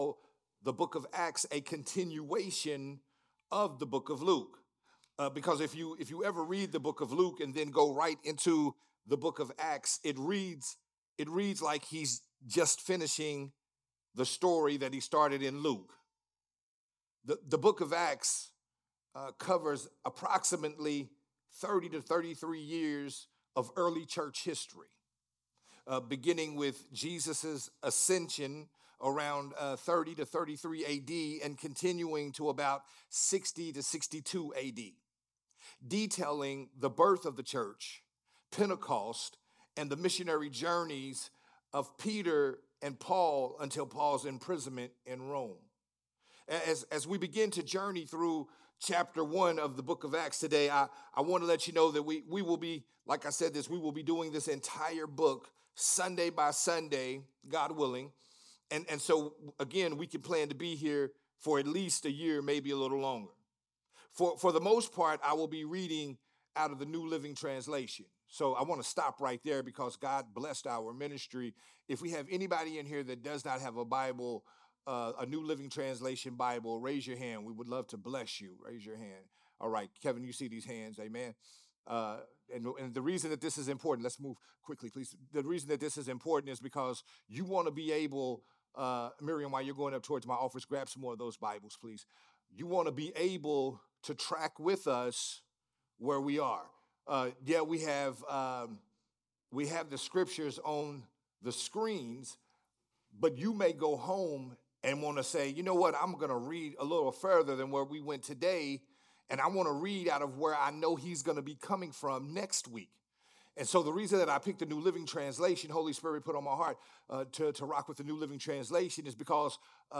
part 2 of the sermon series
recorded at Growth Temple Ministries